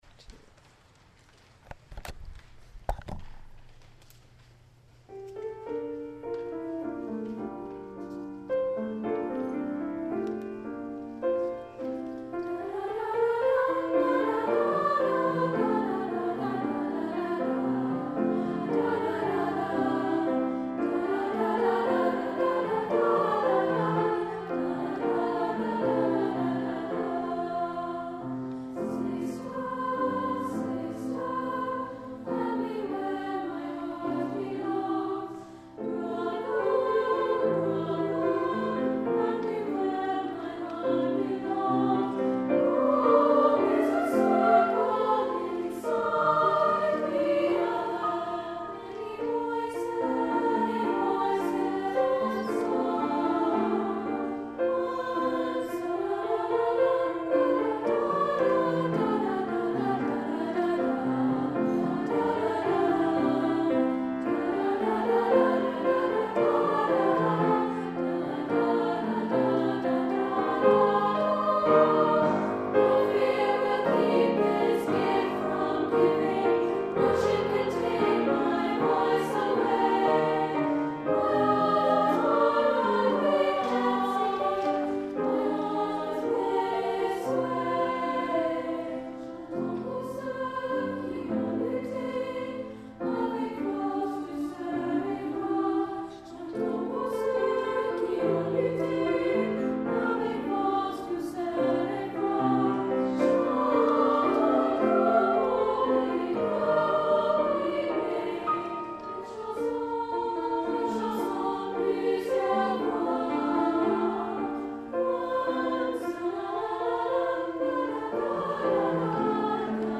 For: SA or SATB with Piano
Available from Rhythmic Trident Music Publishing for SA or SATB, with piano.